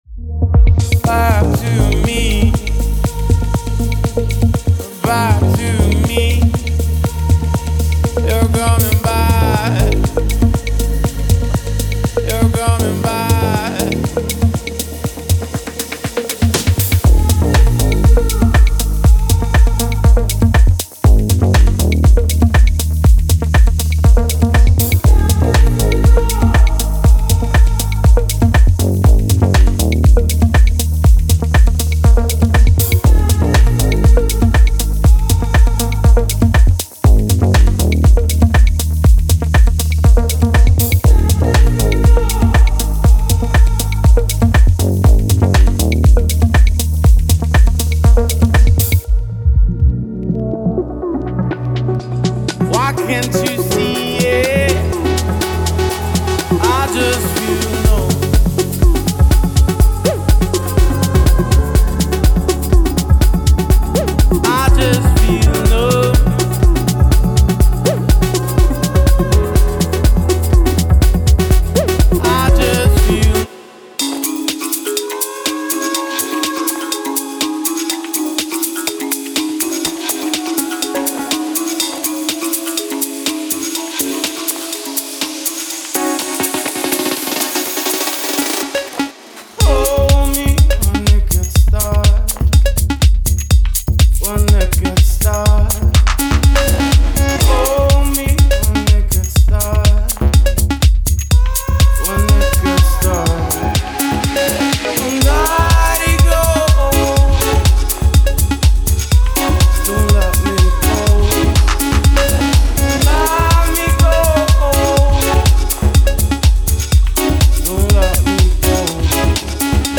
Genre:Tech House
グルーヴィーなシンセやサブベースラインで基礎を築き、トラックにしっかりとした土台を与えましょう。
エレクトリックキーボード、アナログシンセサイザー、カリンバ、プラック、コード、ピアノなどが含まれています。
デモサウンドはコチラ↓